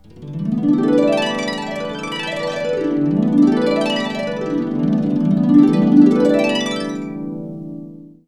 HARP ANX ARP.wav